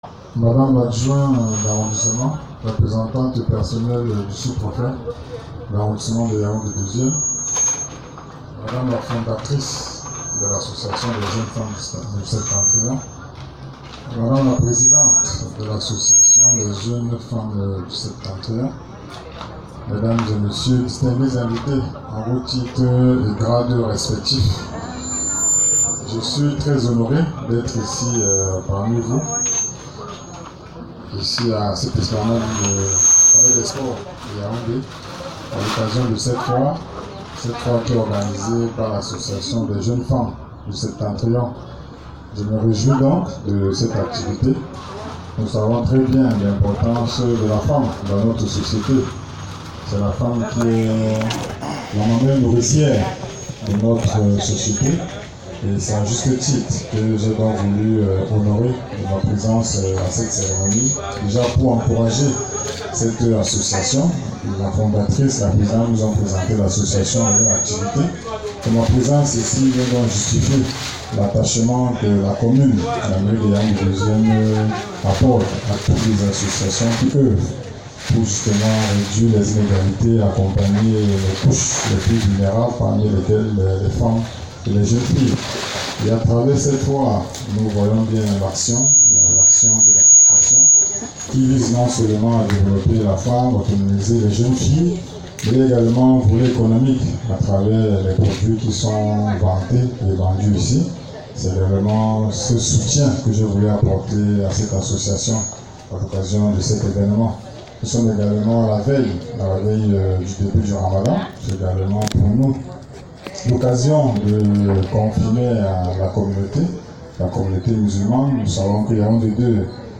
La cérémonie inaugurale, empreinte de solennité, s’est tenue en présence des autorités locales, symbolisant l’adhésion institutionnelle à cette initiative en faveur de l’entrepreneuriat féminin.
Mot de Yannick Ayissi,  Maire de la commune d’arrondissement de Yaoundé II